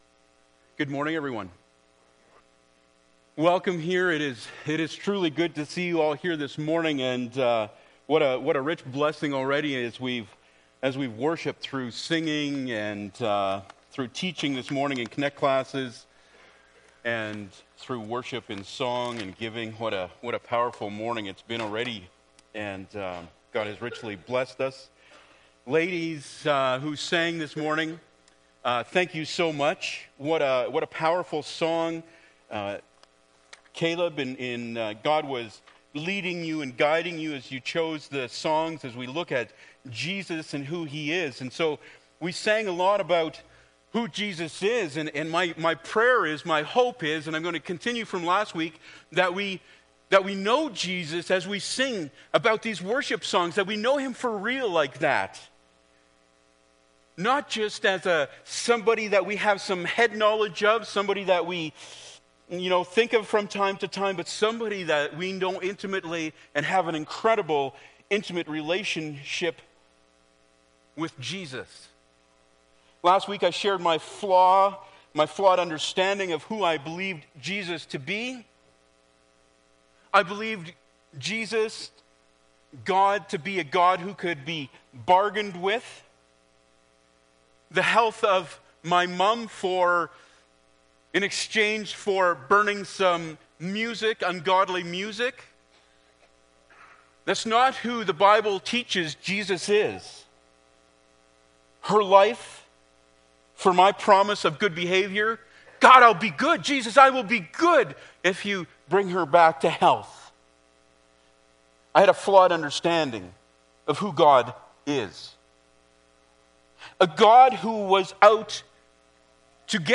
20 Service Type: Sunday Morning Bible Text